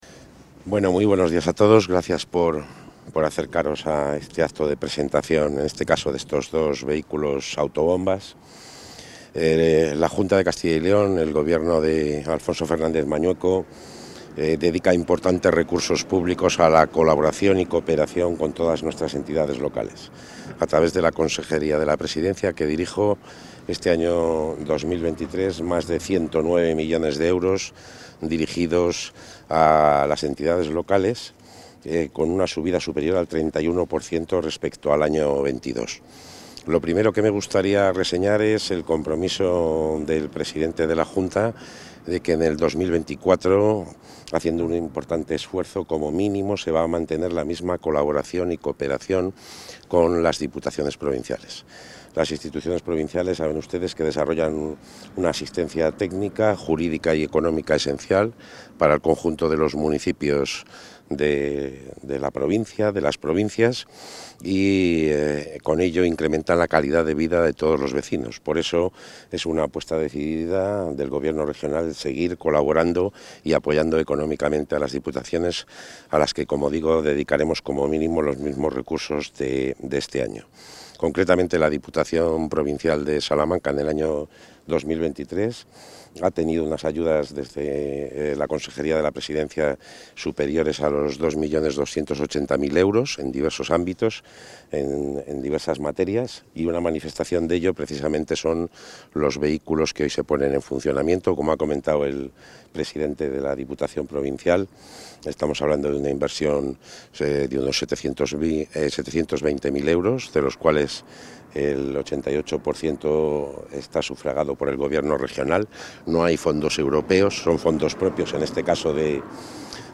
Intervención del consejero.
El consejero de la Presidencia, Luis Miguel González Gago, ha asistido a la presentación de dos nuevos vehículos autobomba que la Diputación de Salamanca ha adquirido con fondos aportados por esta Consejería.